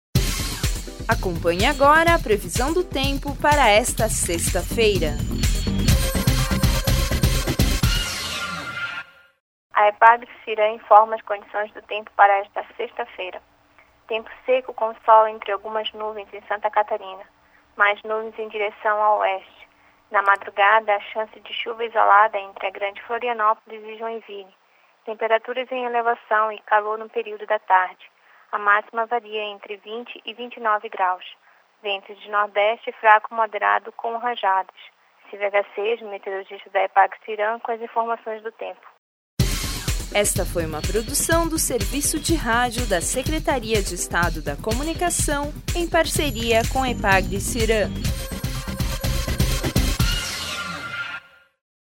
Previsão do tempo para sexta-feira, 1/11/2013